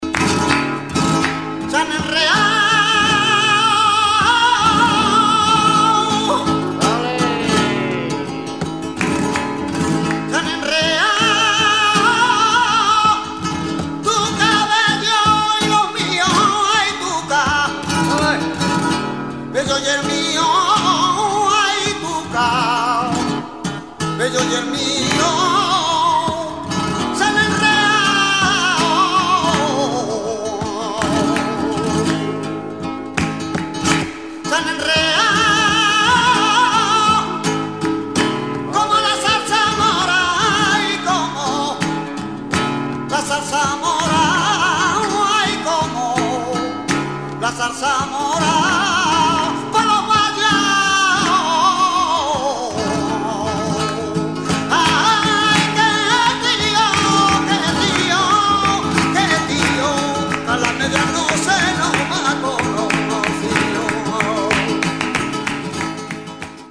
guitarra